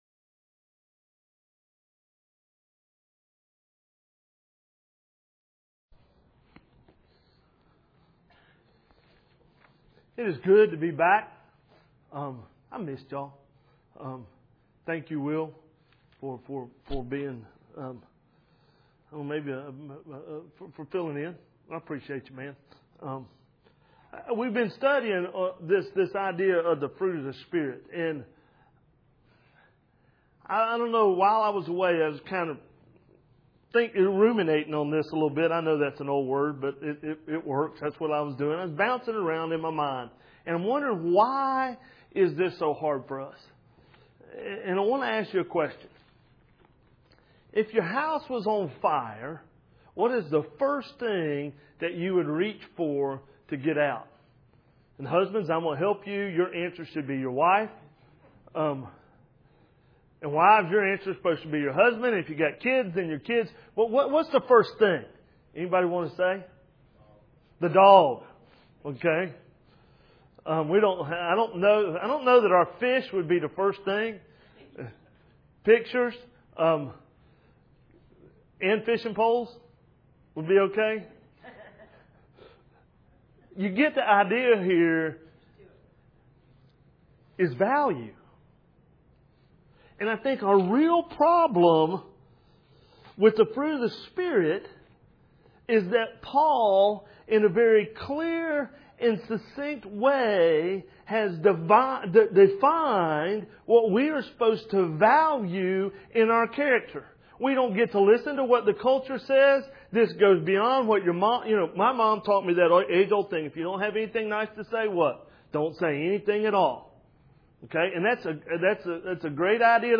Sermons – 2014 – Villa Rica Community Church